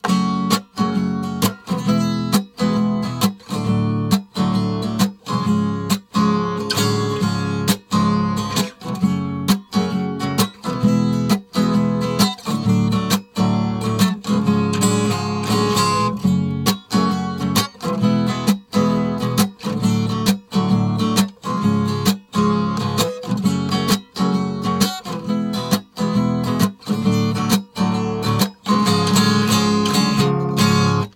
Вот все которые сталкеры играют у костра.